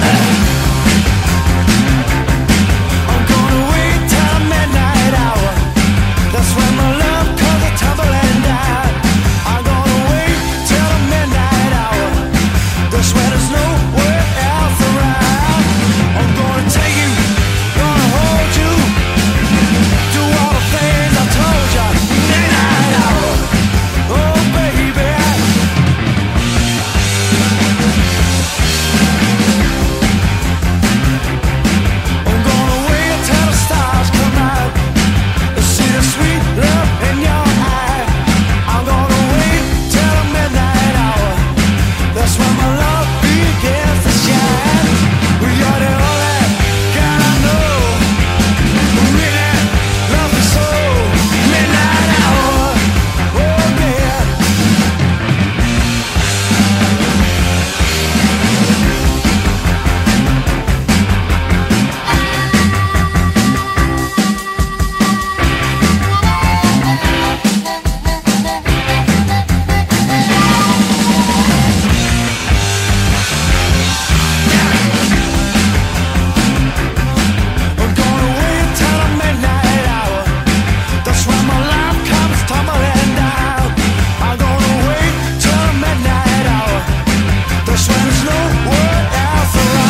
ガレージーでかっこいい94年ネオモッド・コンピ！